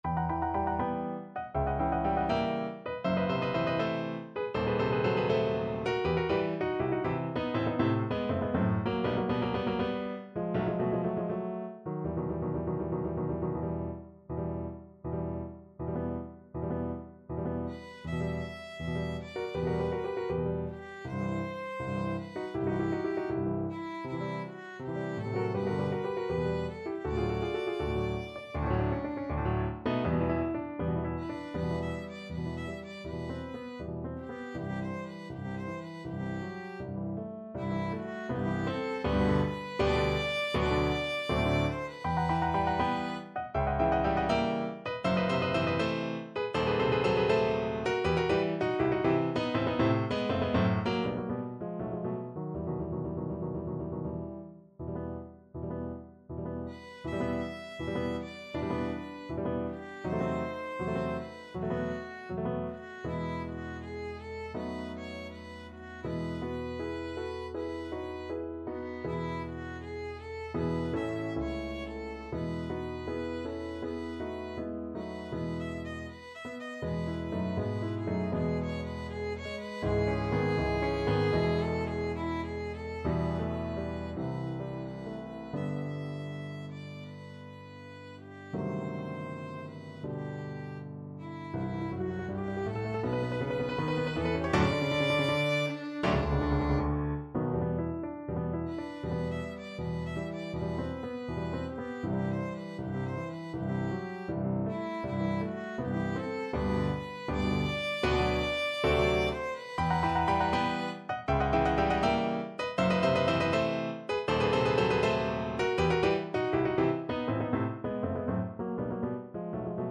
Violin version
4/4 (View more 4/4 Music)
~ = 100 Moderato =80
Violin  (View more Intermediate Violin Music)
Classical (View more Classical Violin Music)